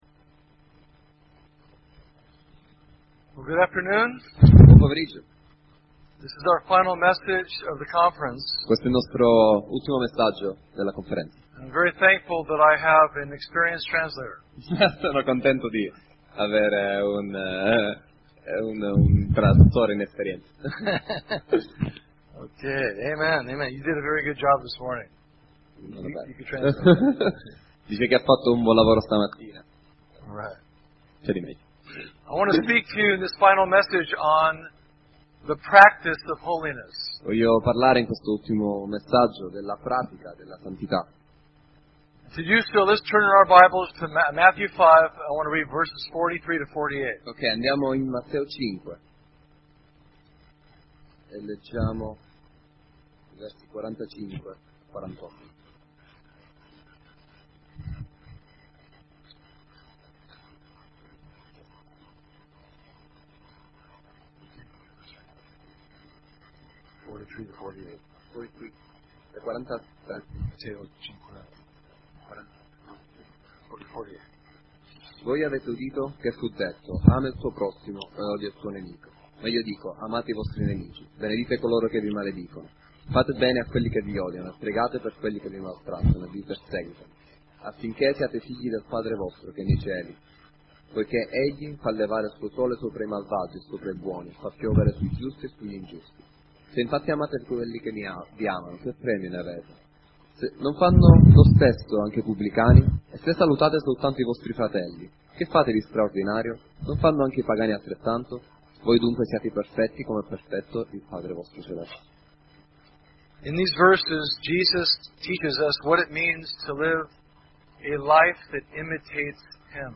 conf_coramdeo2014_7-Domenica-pome-Low-quality.mp3